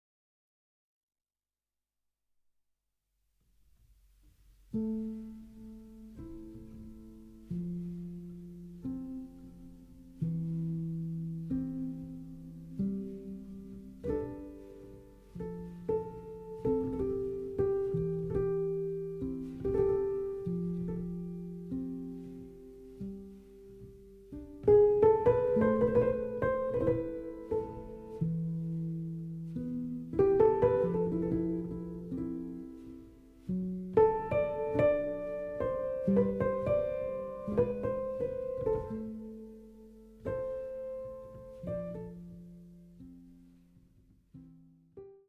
ポスト・クラシカル
一歩引いたところから自分を、世界を見る、静かな熱量。